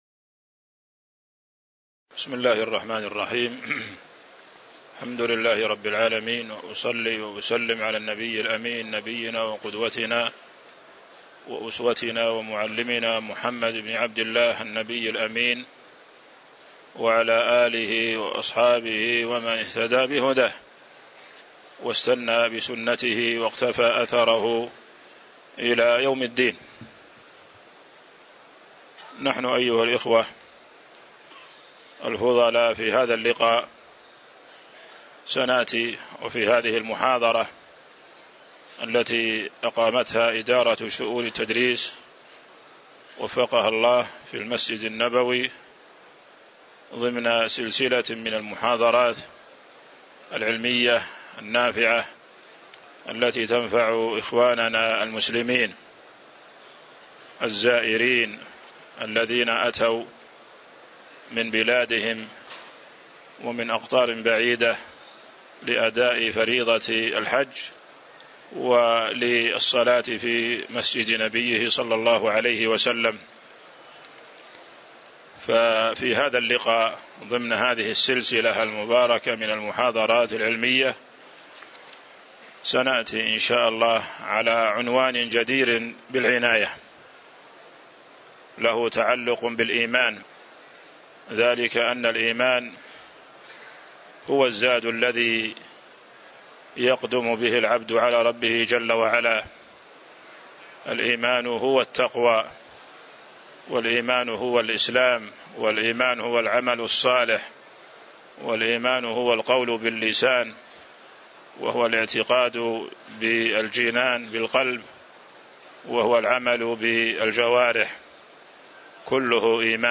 تاريخ النشر ٢٣ ذو الحجة ١٤٤٤ هـ المكان: المسجد النبوي الشيخ